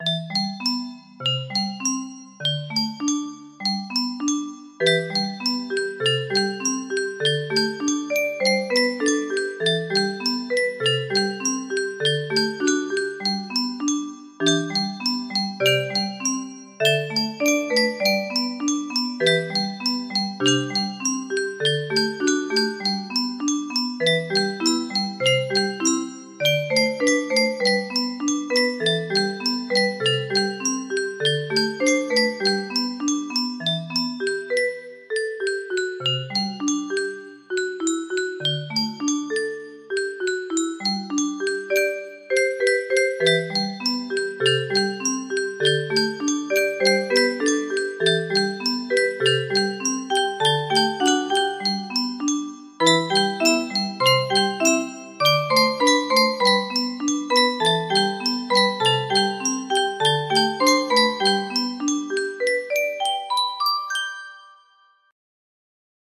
music box melody
Full range 60